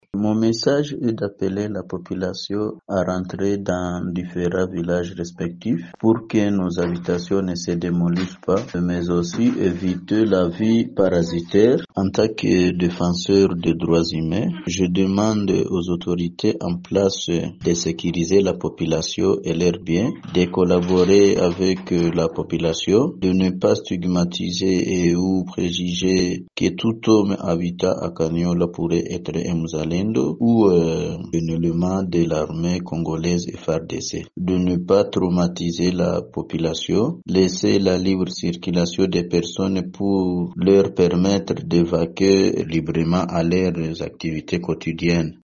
dans un entretien avec Radio Maendeleo mercredi 24 septembre 2025.